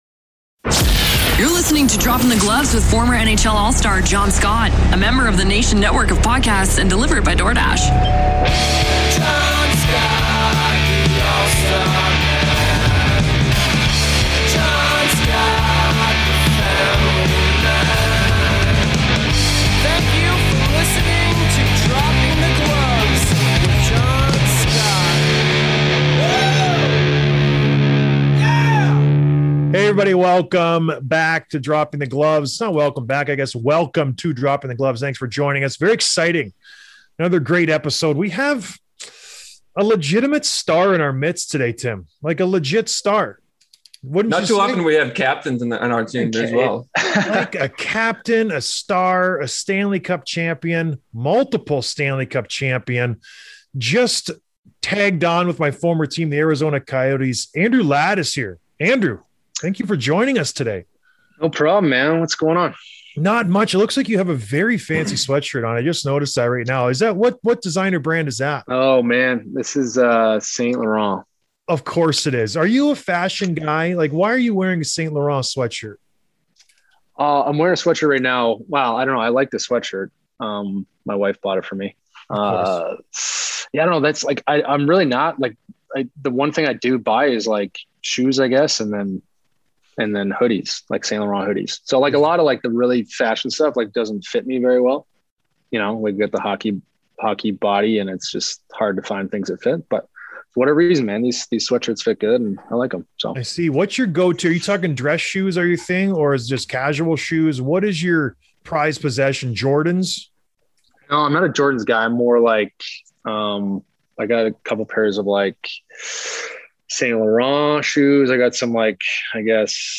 Interview with Andrew Ladd, Phoenix Coyotes